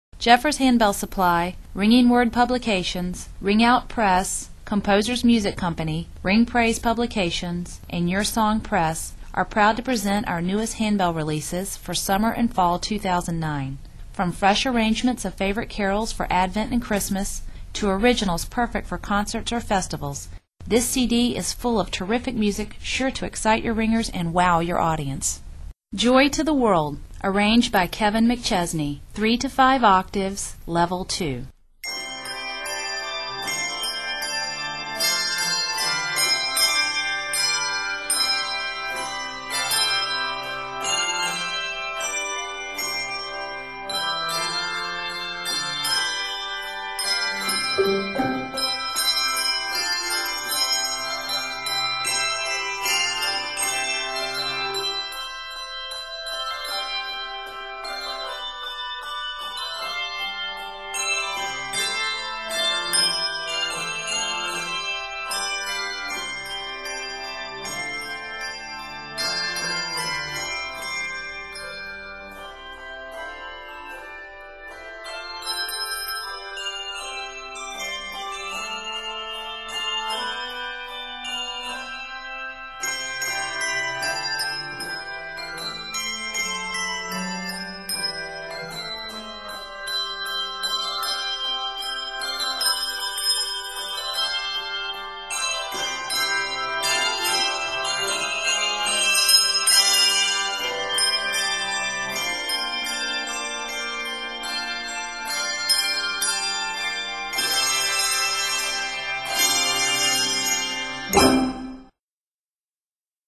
this lively, upbeat setting